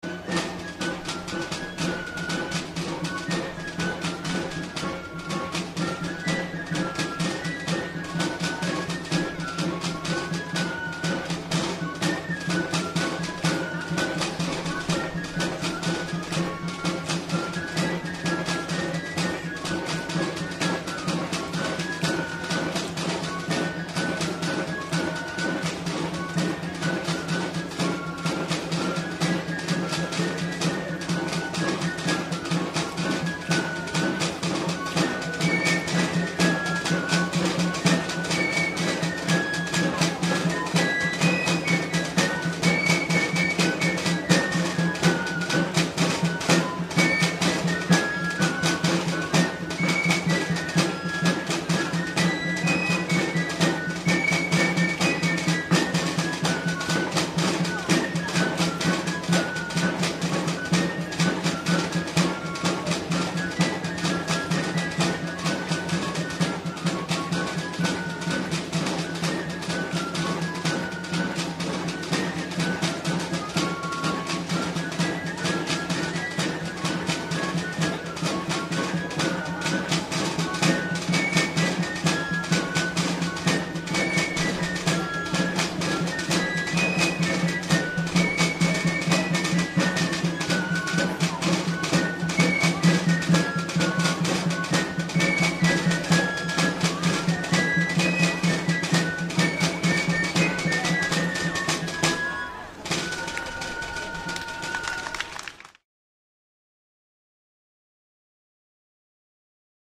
Txistularis de Ituren Dantza Luze de Ituren.
ITUREN-Dantza luzea-Fandangoa.mp3